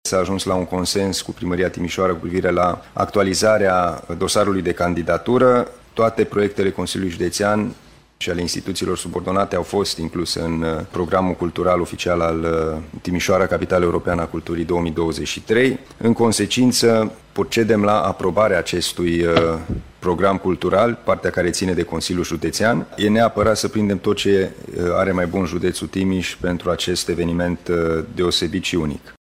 În consecință consilierii județeni au actualizat programul pentru 2023, care cuprinde 20 de proiecte, spune liderul administrației judenețe, Alin Nica.